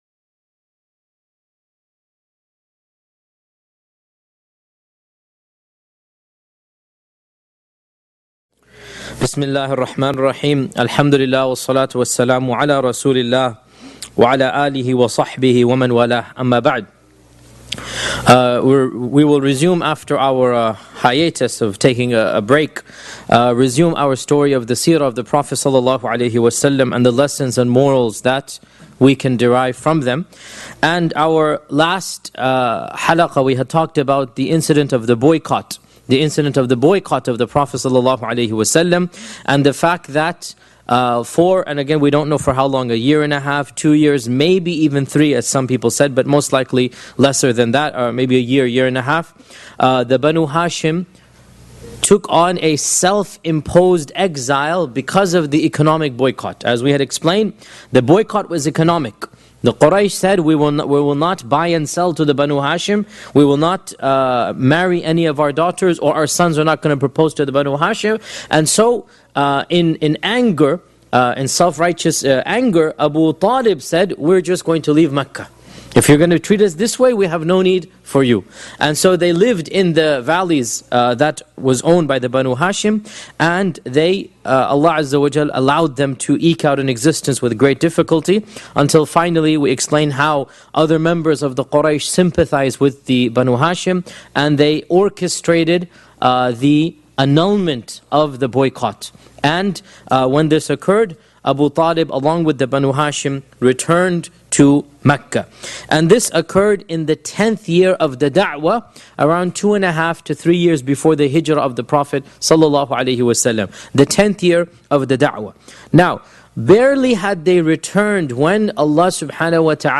767 views High Quality: Download (233.7 MB) Medium Quality: Download (38.37 MB) MP3 Audio (00:58:56): Download (42.48 MB) Transcript: Download (0.25 MB) Seerah of Prophet Muhammad 18 Shaykh Yasir Qadhi gives a detailed analysis of the life of Prophet Muhammed (peace be upon him) from the original sources. Title: The year of sorrow Part 1 – Death of Khadija & Abu Talib Study the biography of the single greatest human being that ever walked the surface of this earth, whom Allah sent as a Mercy to Mankind. This lecture was recorded on 11th January, 2012 Shaykh Yasir Qadhi gives a detailed analysis of the life of Prophet Muhammed (peace be upon him) from the original sources.